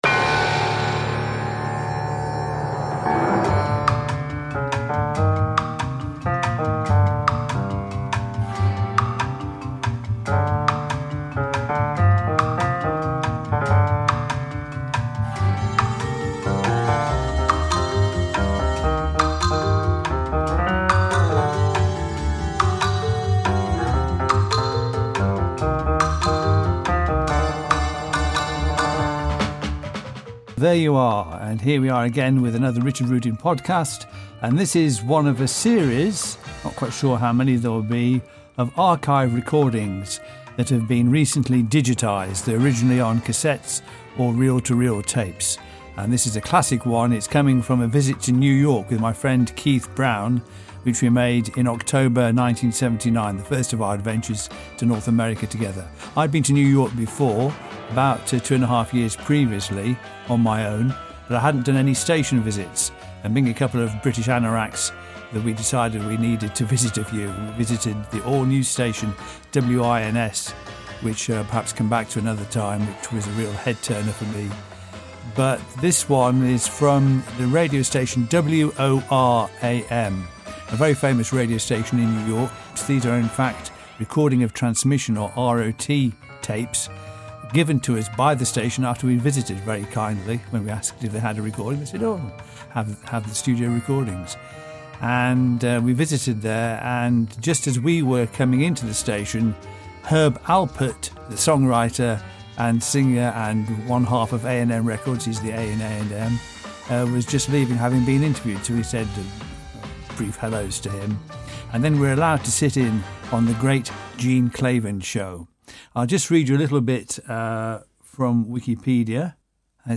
A studio-quality recording of New York legendary radio host Gene Klavan.